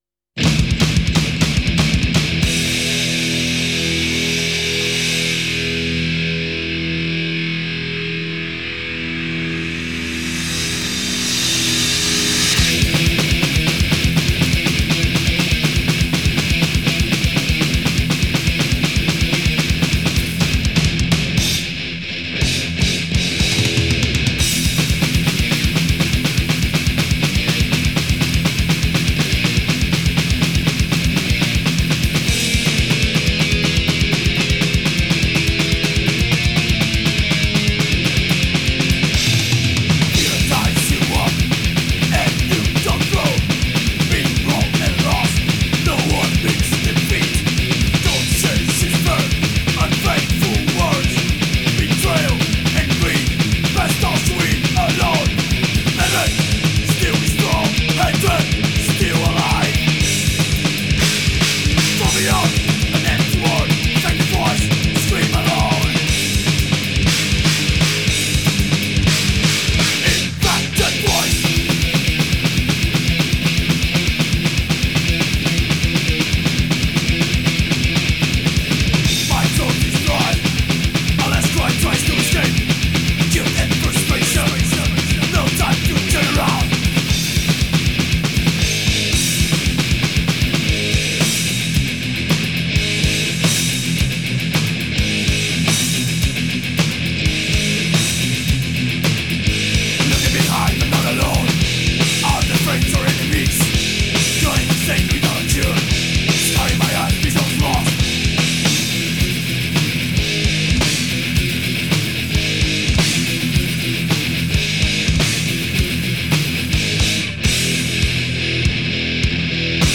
Thrash Metal Groove metal